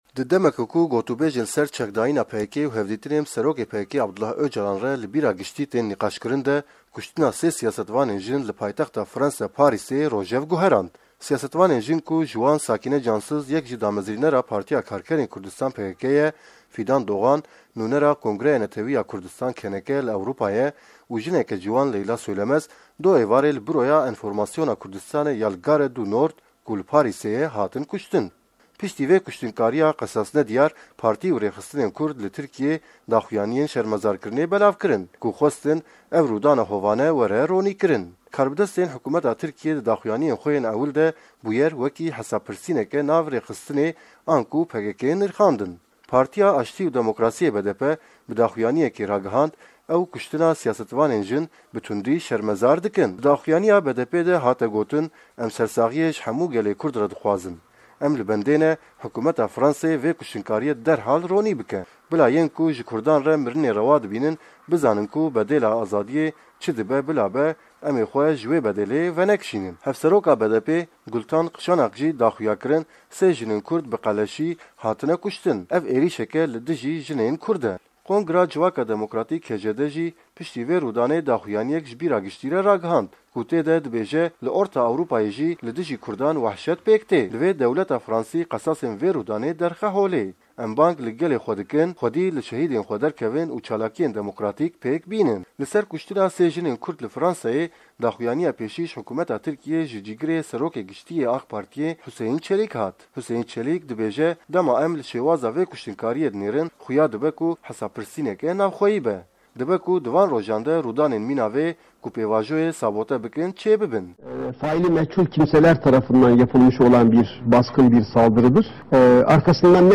Raport